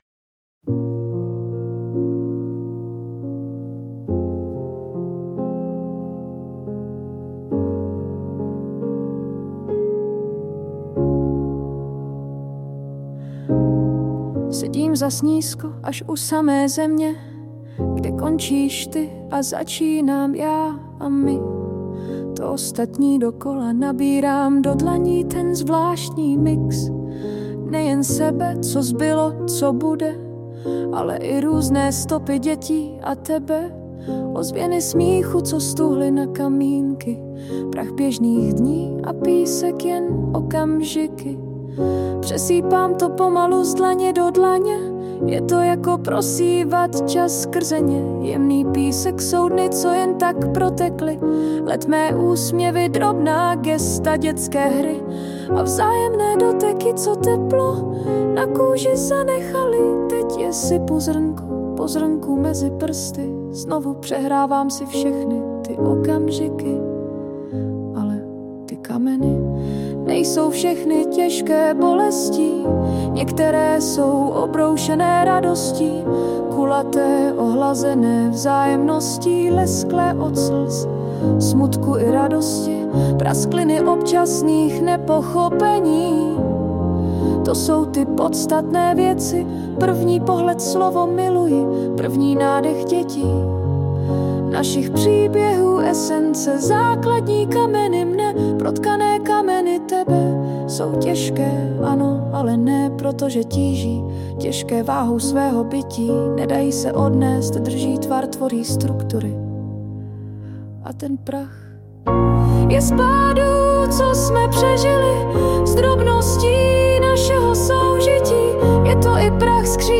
já mívám občas problém s delšími texty, tady zhudebněný se mi krásně poslouchal :)
Je to super a výjimečně mi ani nevadí to AI zhudebnění... prostě to spolu ladí:-)